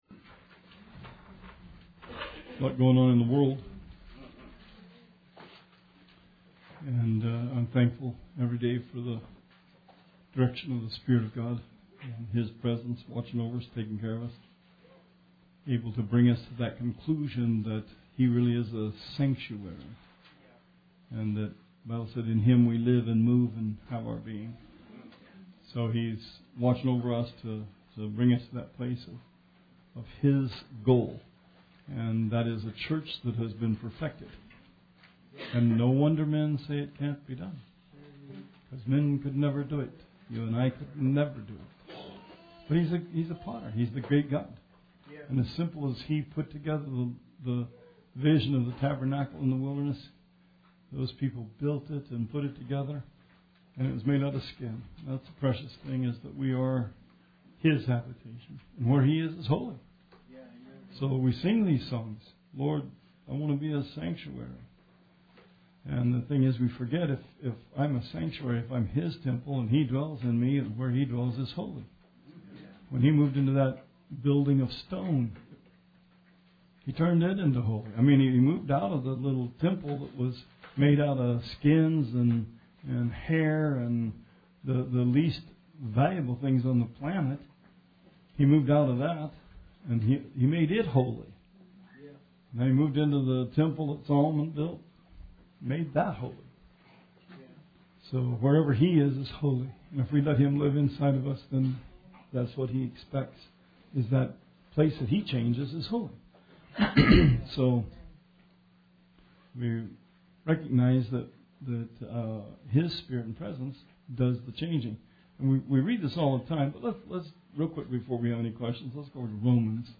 Bible Study 1/24/18